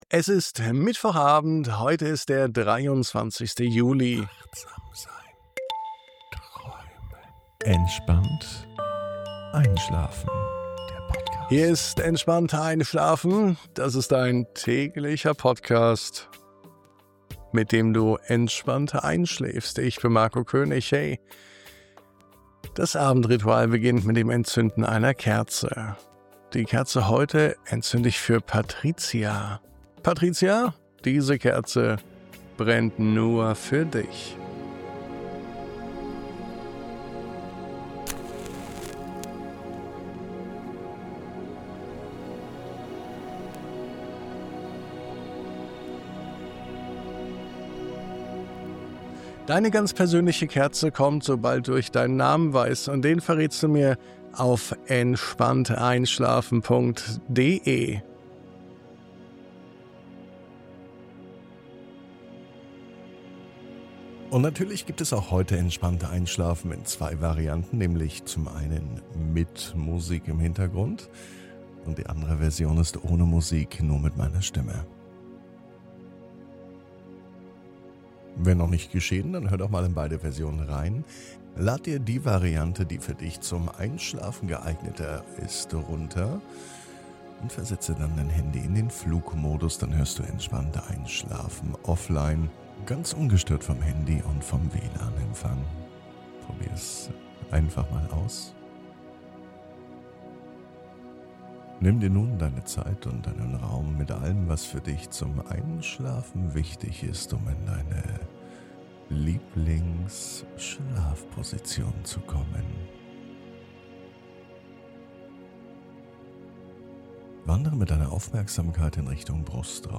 0723_MUSIK.mp3